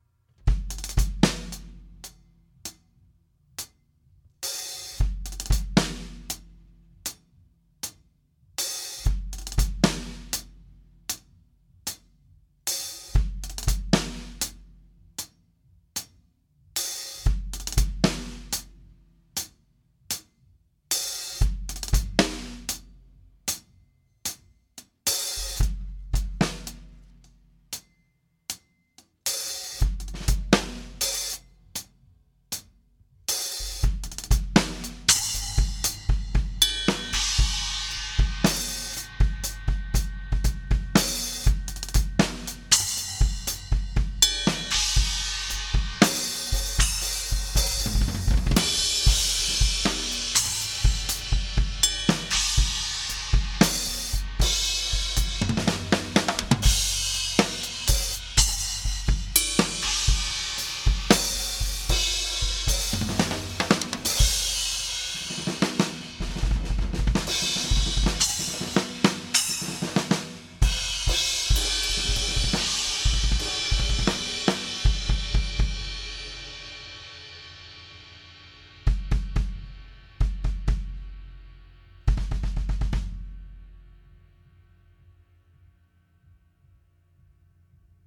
Got the rack polished and everything up to the practice space: